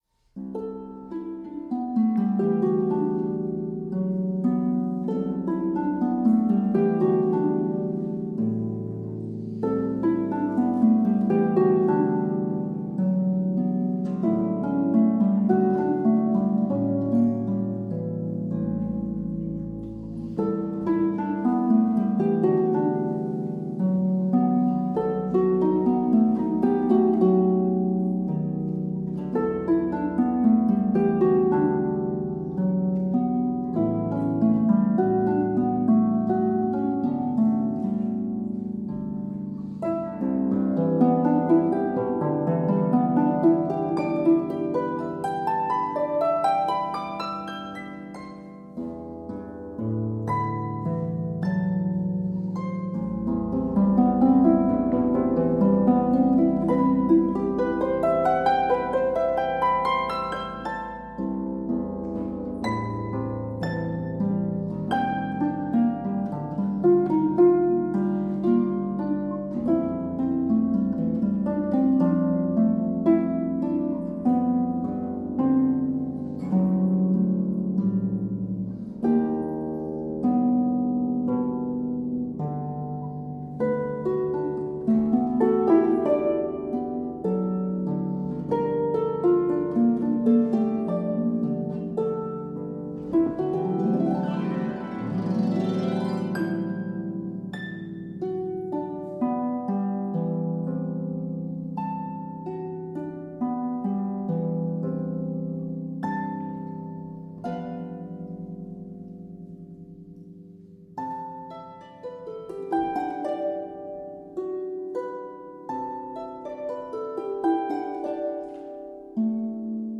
Harp Solo